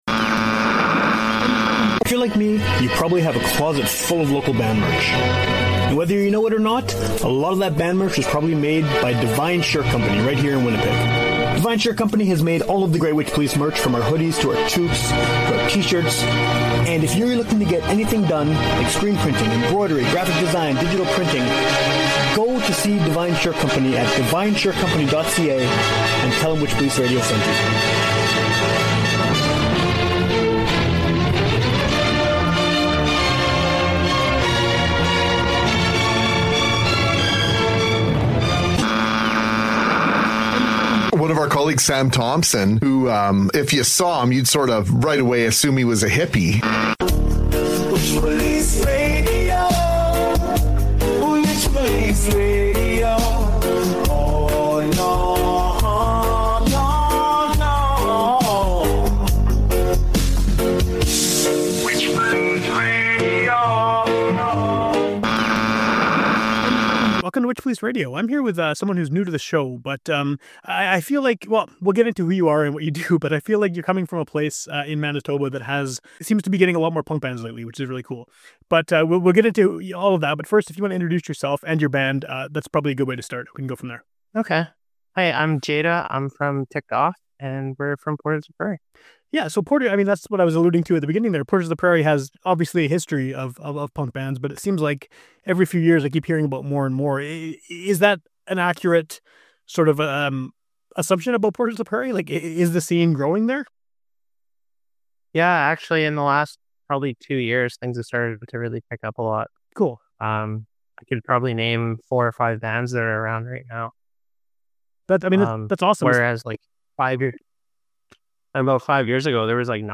Fun conversation